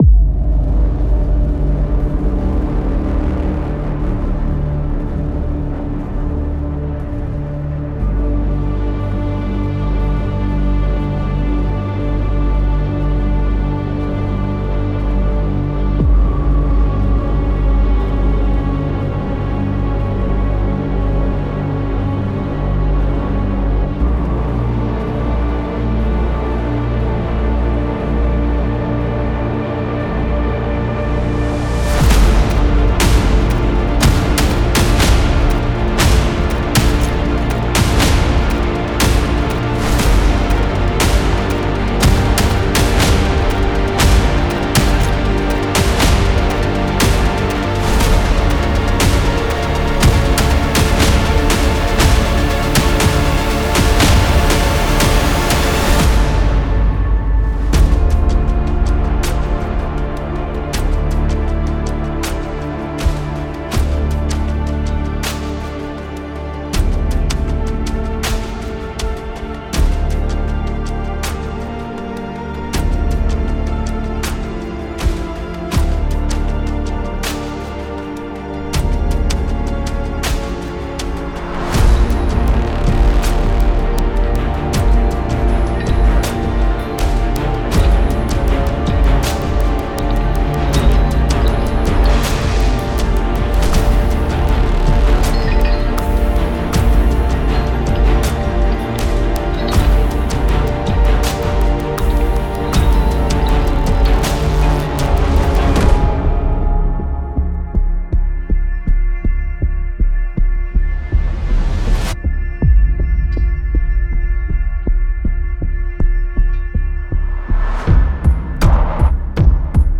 デモサウンドはコチラ↓
Genre:Filmscore
043 Atmospheres
047 Drums
041 Orchestral
029 Synths